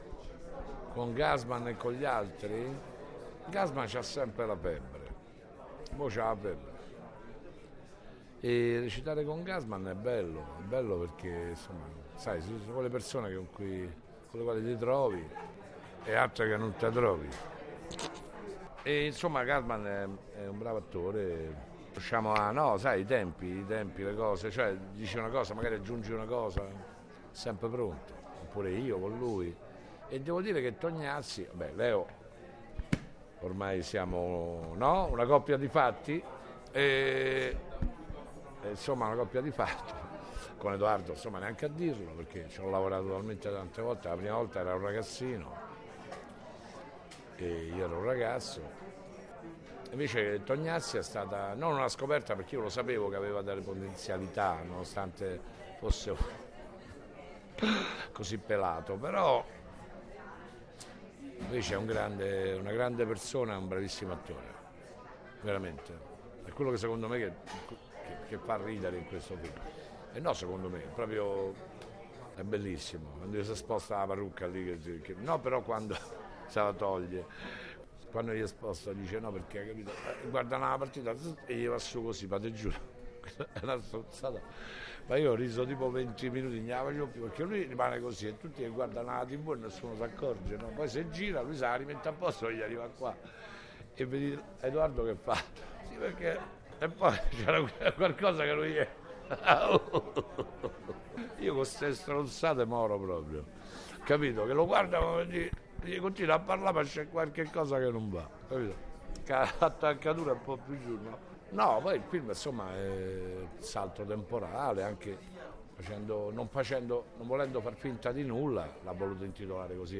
non-ci-resta-che-il-crimine-marco-giallini-parla-del-film.mp3